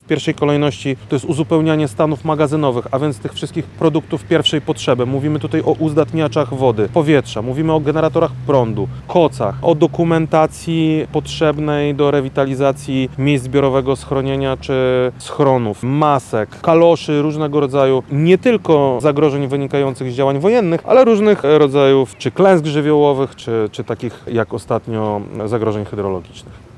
– Potrzeby określiliśmy na podstawie zgłoszeń z samorządów. Potem konsultowaliśmy listy ze strażą pożarną, ministerstwem i wojskiem – powiedział dziś (30.07) w Zamościu wojewoda Krzysztof Komorski.